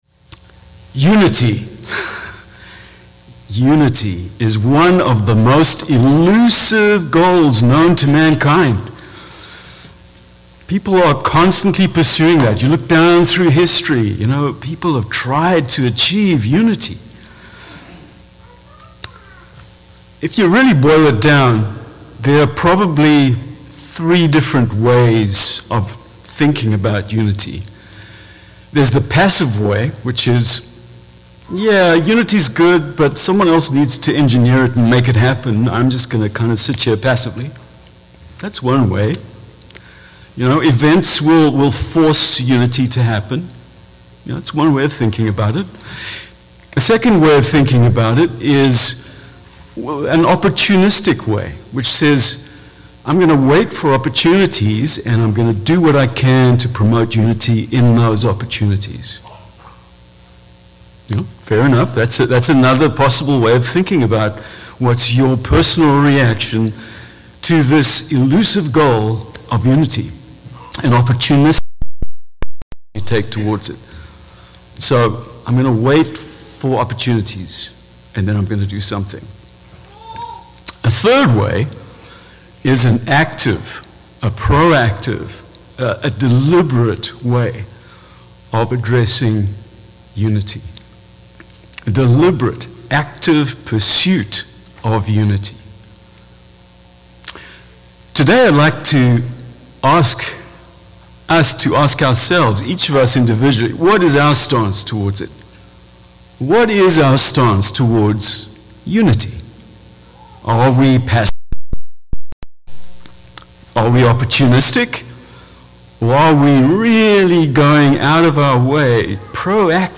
Given in Fort Worth, TX